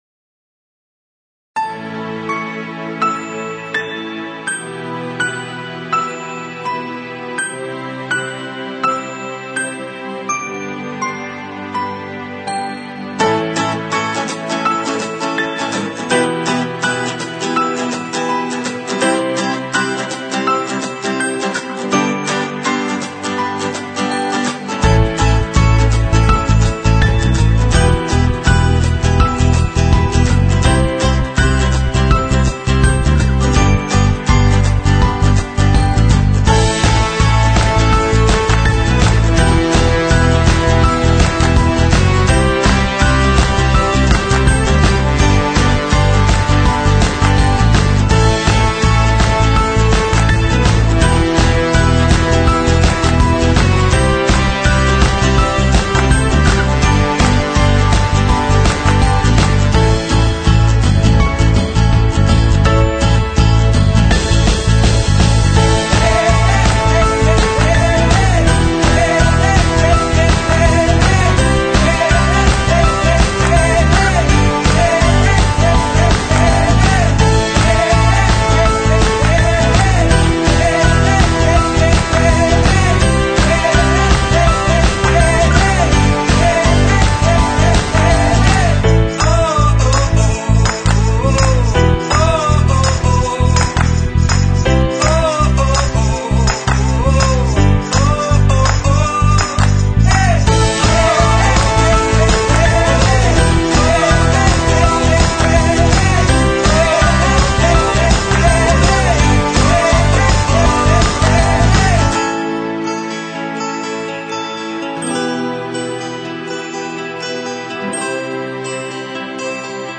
(No Vocals)